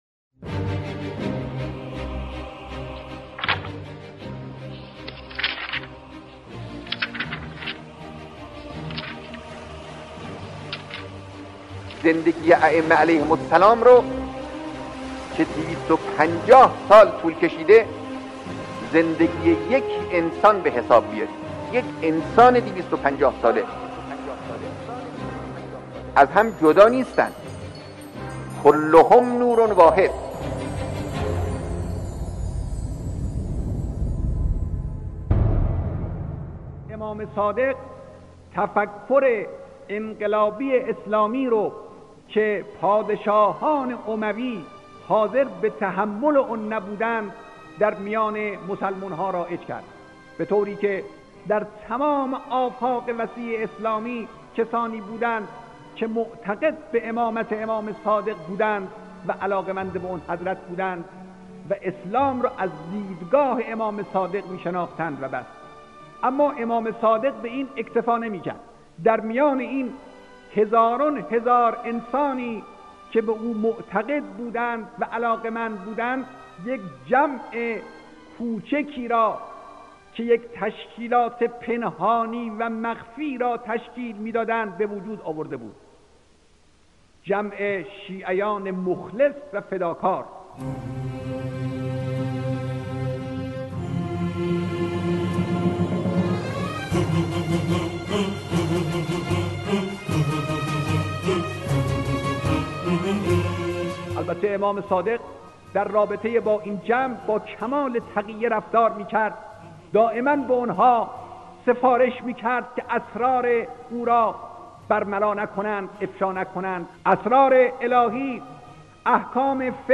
گزیده ای از بیانات رهبر انقلاب